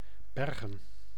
Summary Description Nl-Bergen.ogg male voice pronunciation for " Bergen " .